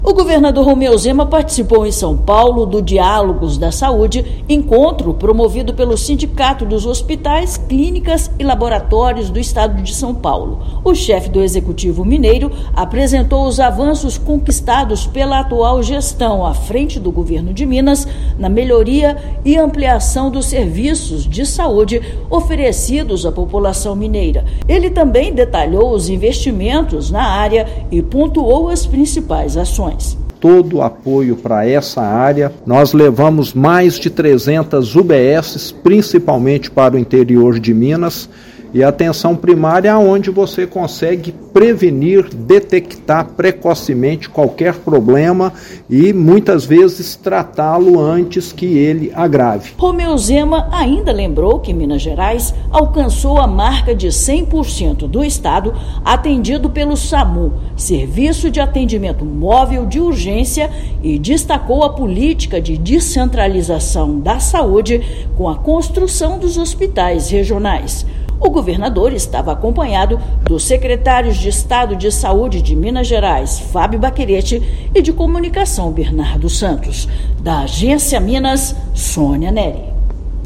Investimento na atenção primária e atendimento de urgência foi destaque durante evento que discutiu os desafios da área no Brasil. Ouça matéria de rádio.